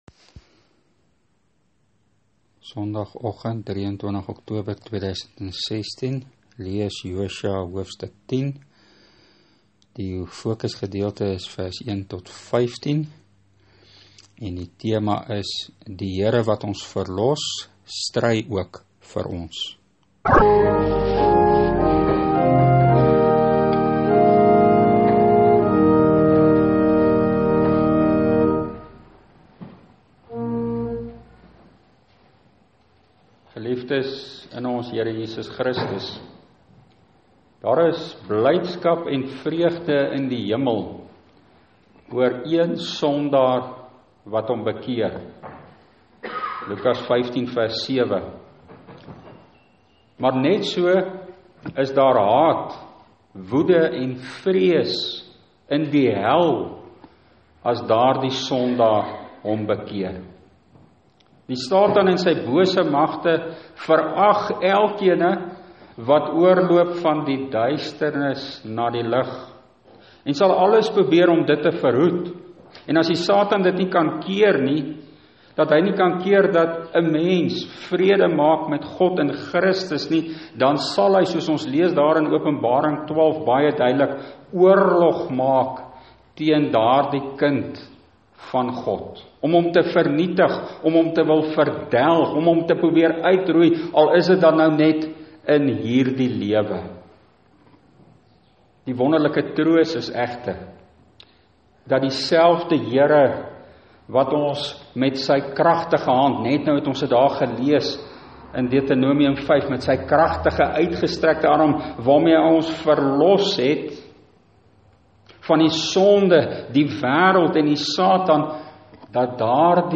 Preekopname (GK Carletonville, 2016-10-23):